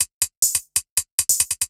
Index of /musicradar/ultimate-hihat-samples/140bpm
UHH_ElectroHatD_140-01.wav